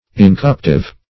Search Result for " incorruptive" : The Collaborative International Dictionary of English v.0.48: Incorruptive \In`cor*rupt"ive\, a. [L. incorruptivus.] Incorruptible; not liable to decay.